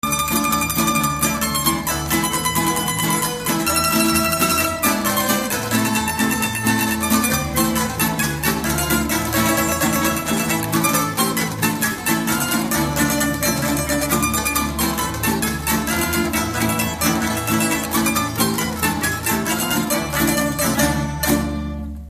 Dallampélda: Hangszeres felvétel
Alföld - Bács-Bodrog vm. - Dávod
tambura (prím)
klarinét (B)
tambura (basszprím)
tamburakontra
tamburabőgő
Műfaj: Ugrós
Stílus: 4. Sirató stílusú dallamok
Kadencia: 6 (5) 2 1